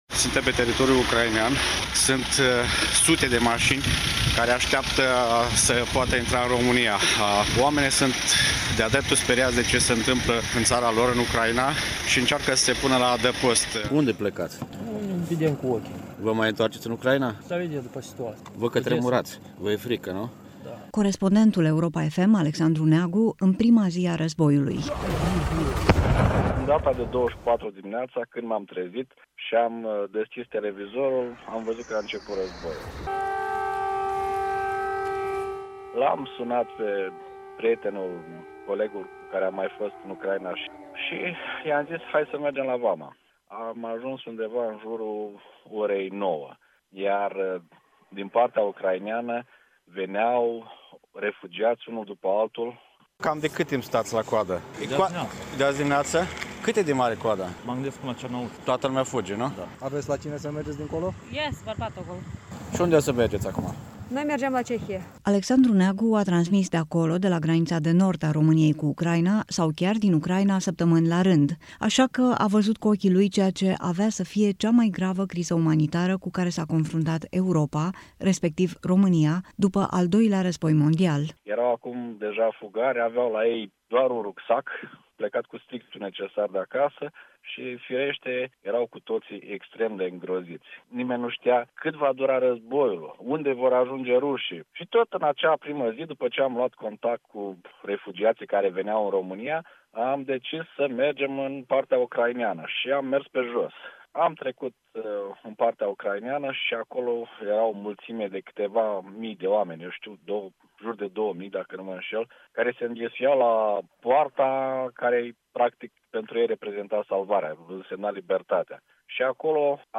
Reportaj, Lumea Europa FM: Un an de război. Refugiații / AUDIO